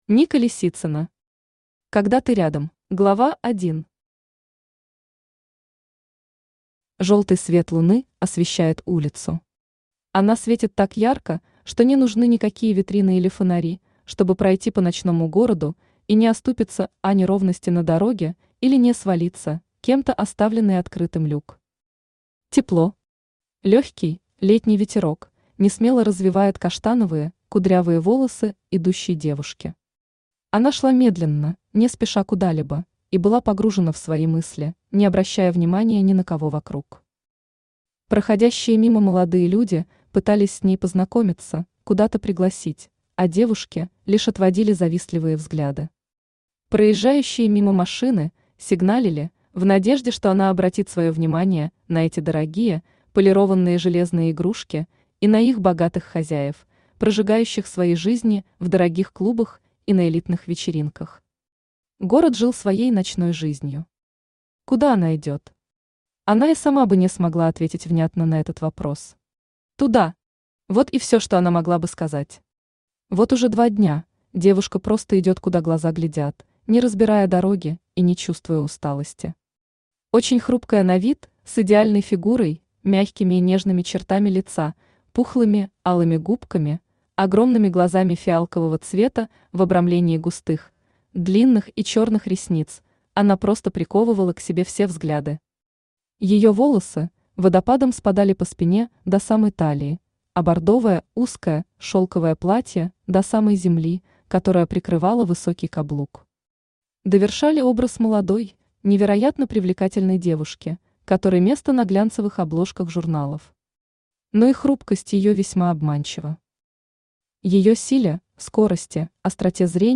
Аудиокнига Когда ты рядом | Библиотека аудиокниг
Aудиокнига Когда ты рядом Автор Ника Лисицына Читает аудиокнигу Авточтец ЛитРес.